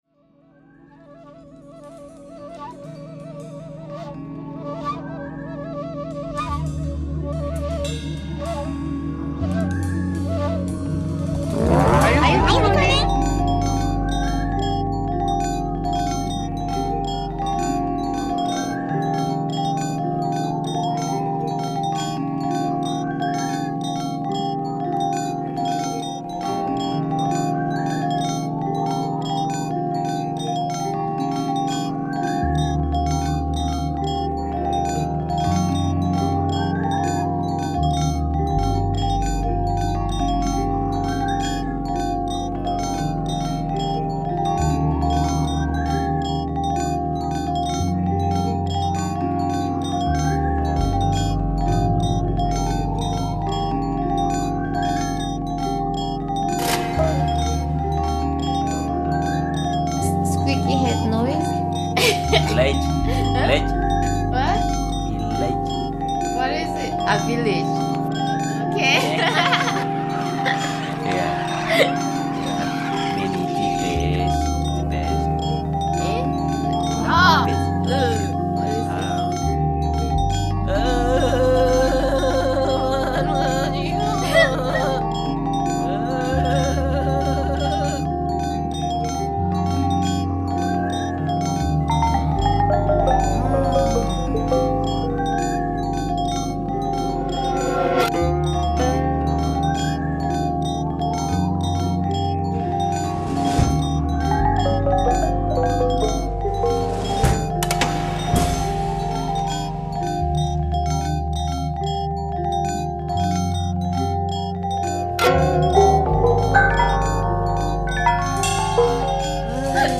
Its theme relates to a layered series of conversations, all of which contain some element of instruction.
The other main sound source was provided by single note samples of the instruments of Victoria Unversity of Wellington's gamelan (Padang Moncar).
drums
suling (flute)